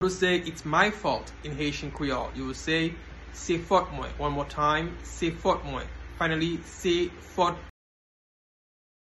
Listen to and watch “Se fòt mwen” pronunciation in Haitian Creole by a native Haitian  in the video below:
Its-my-fault-in-Haitian-Creole-Se-fot-mwen-pronunciation-by-a-Haitian-teacher.mp3